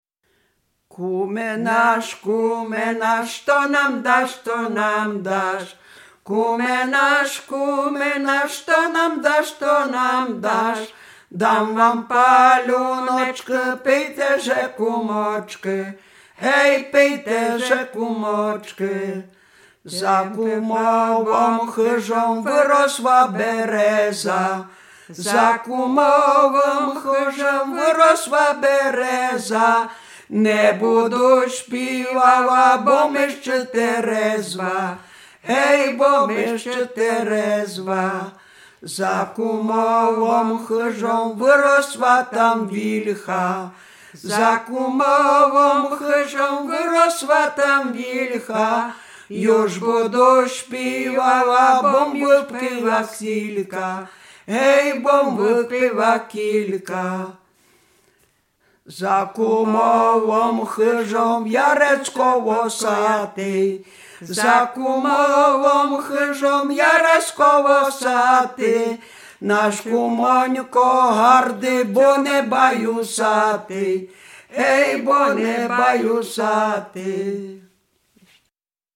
Śpiewaczki z Sobina
Dolny Śląsk, gmina Polkowice, wieś Sobin
Chrzcinne
przesiedleńcy chrzcinne przyśpiewki